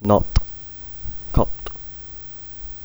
Short A - similar to the 'u' in 'cUt' (natt, katt)
natt_katt.wav